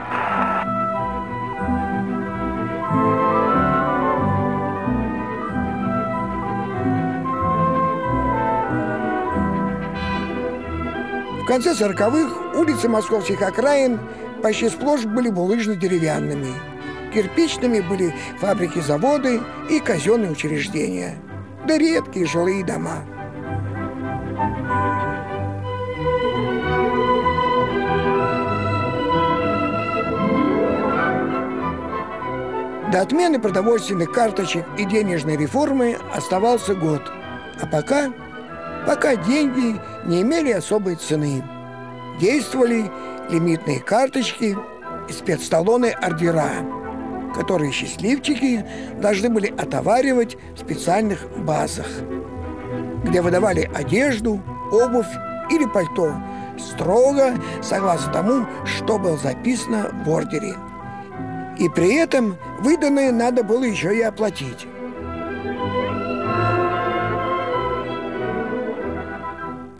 Мелодия служит сопровождением для диктора.
(Закадровый текст читает Лев Дуров)